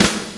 Tuned snare samples Free sound effects and audio clips
• Clean Acoustic Snare Sample F Key 42.wav
Royality free snare single hit tuned to the F note. Loudest frequency: 2959Hz
clean-acoustic-snare-sample-f-key-42-p5t.wav